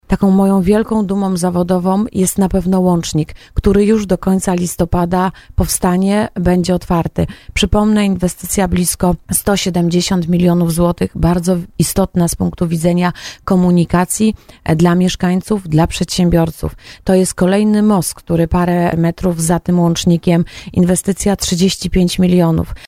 Pytana o tę kwestię w programie Słowo za Słowo powiedziała, że nie ma takich przymiarek.